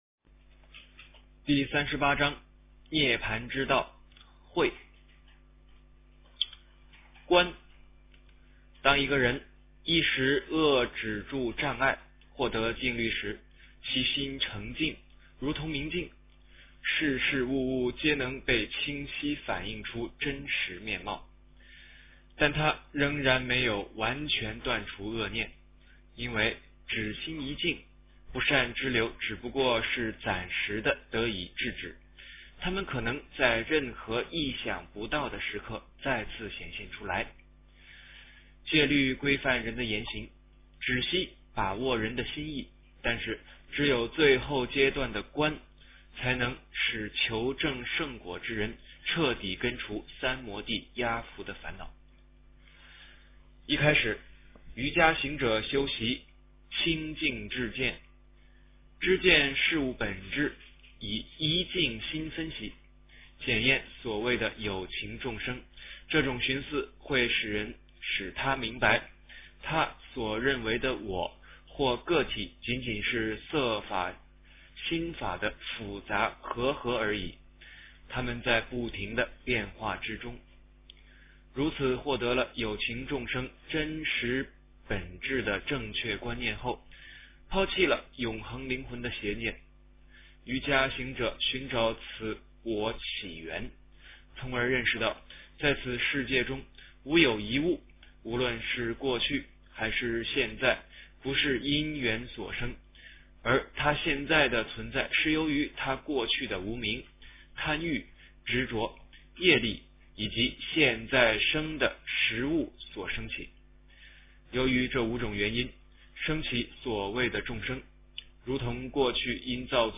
觉悟之路38 诵经 觉悟之路38--有声佛书 点我： 标签: 佛音 诵经 佛教音乐 返回列表 上一篇： 早课1-楞严咒+大悲咒 下一篇： 金刚经 相关文章 献供赞（唱诵）--文殊院 献供赞（唱诵）--文殊院...